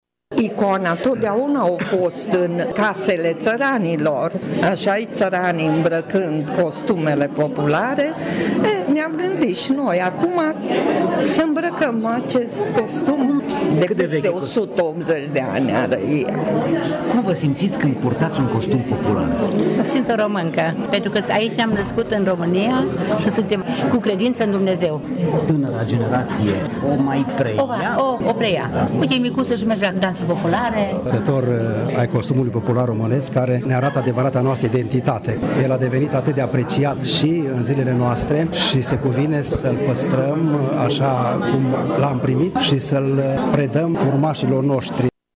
La evenimentul organizat miercuri la sediul Uniunii din Tg. Mureș au fost prezenți pensionari îmbrăcați în costume populare vechi, unele și de o jumătate de secol, moștenite de la părinți și bunici.